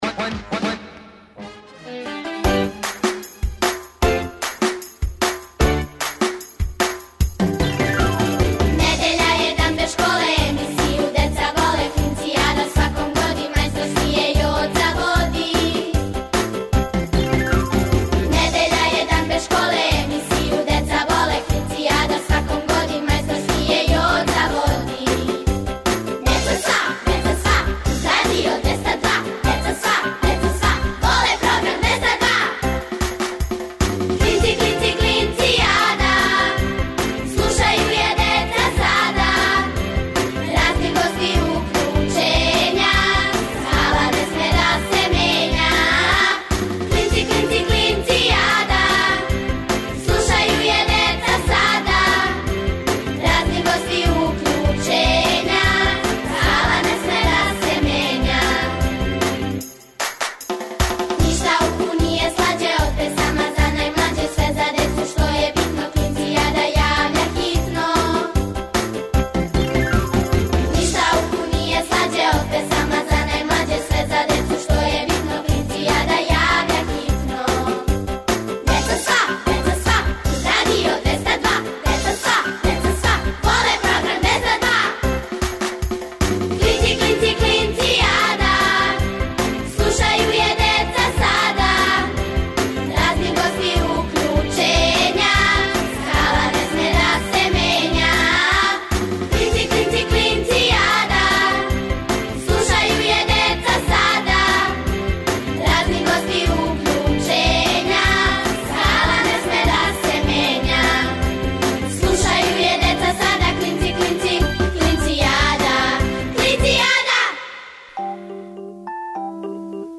Сваке недеље уживајте у великим причама малих људи, бајкама, дечјим песмицама.